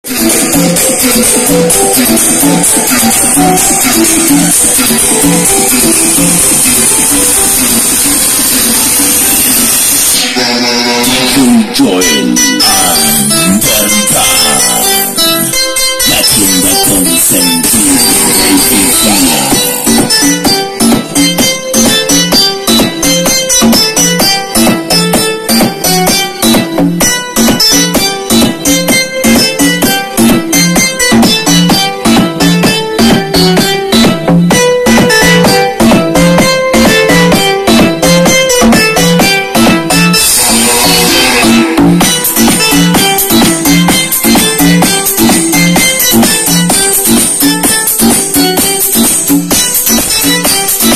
prueba de sonido EL THANOS sound effects free download
CAR AUDIO